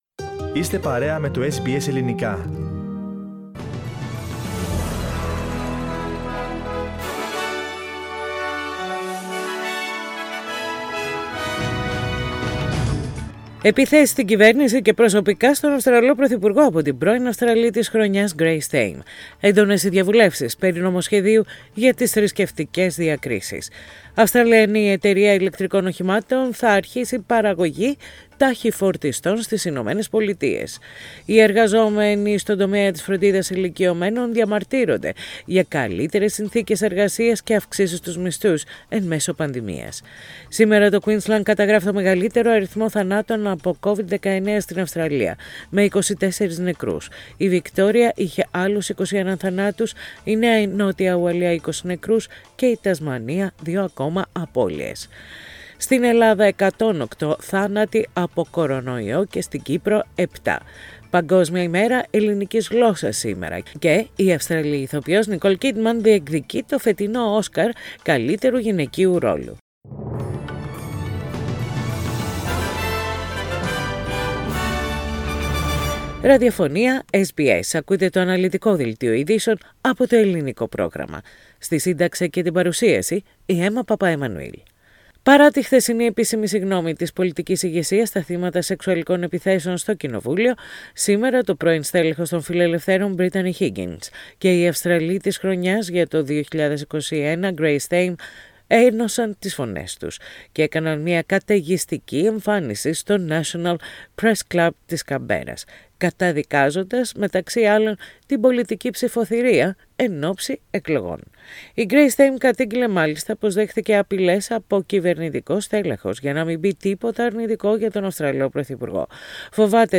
News in Greek. Source: SBS Radio